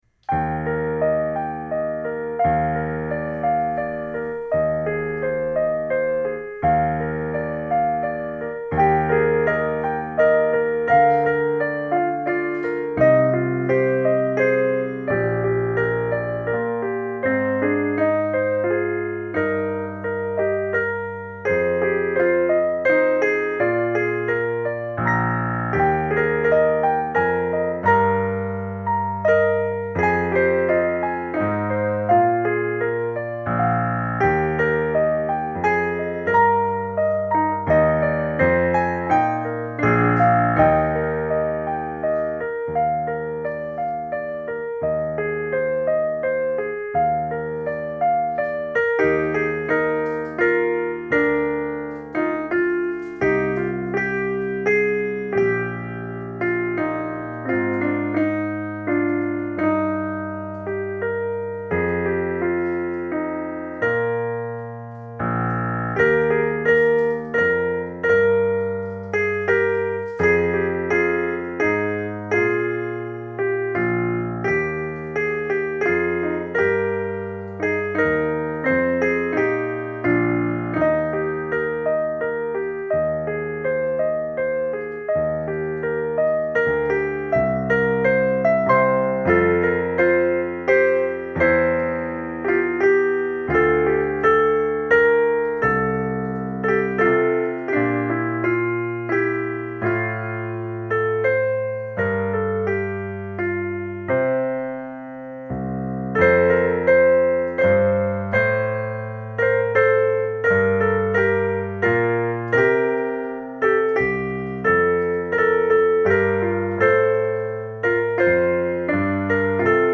5th grade promotion song